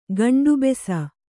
♪ gaṇḍubesa